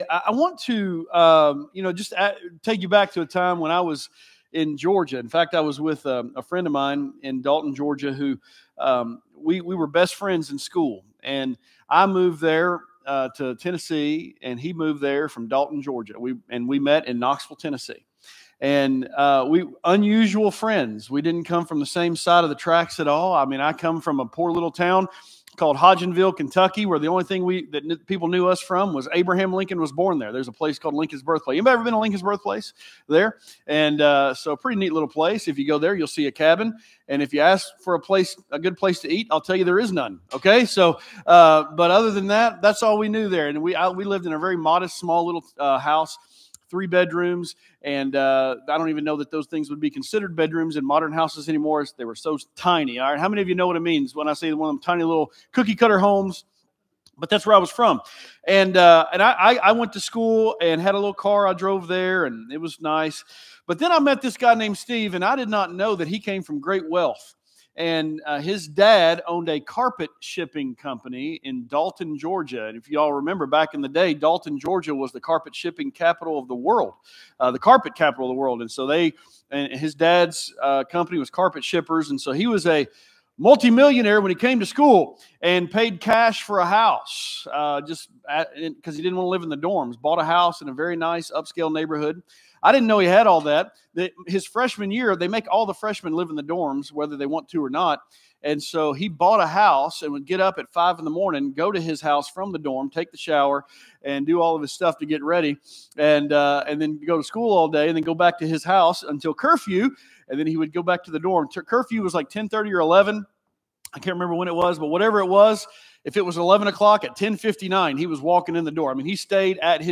Sermons | Grace Baptist